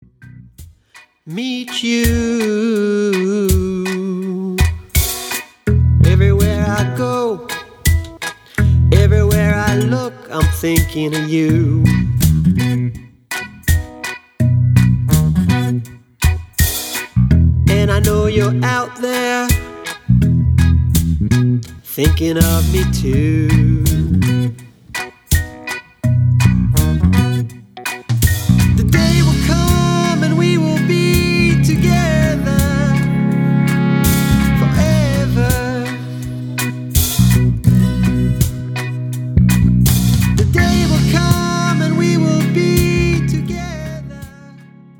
Contemporary Jewish music with a rock/folk vibe.